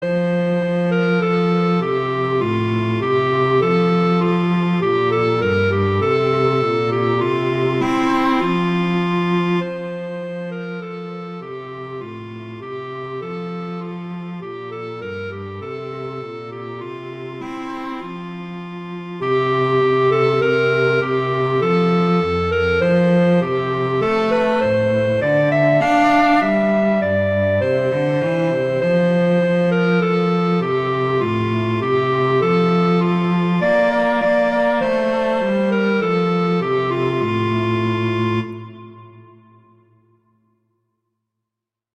arrangements for clarinet and cello